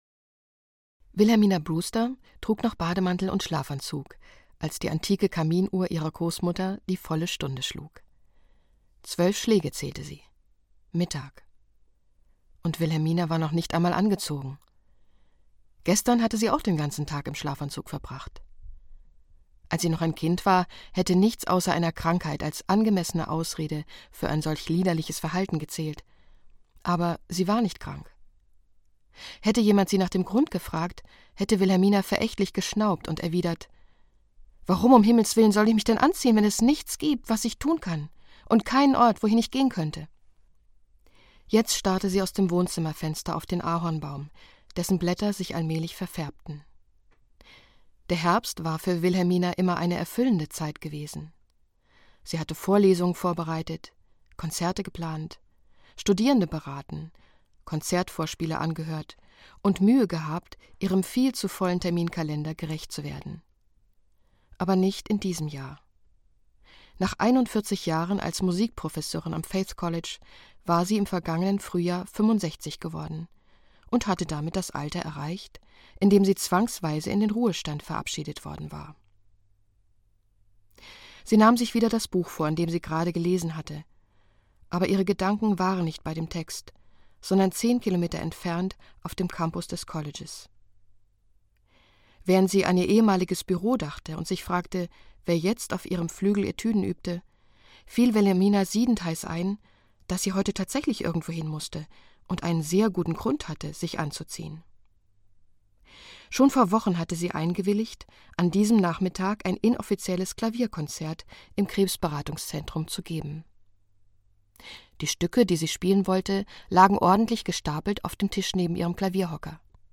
Ein letzter Flug - Lynn Austin - Hörbuch